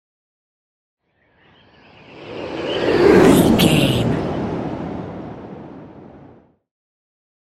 Whoosh airy long
Sound Effects
whoosh